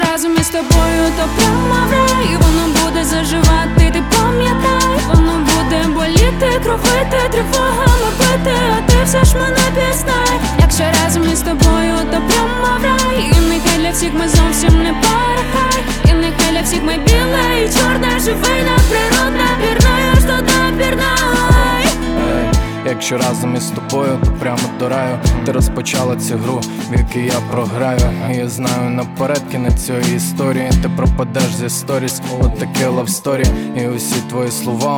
Жанр: Хип-Хоп / Рэп / Русский рэп / Русские